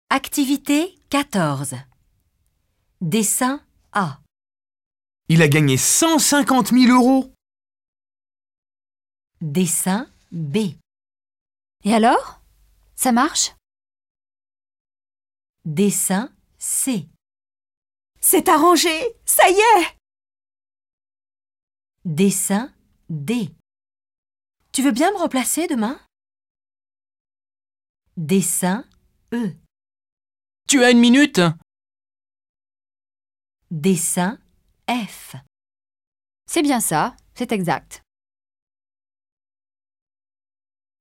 Comprendre une interaction entre locuteurs natifs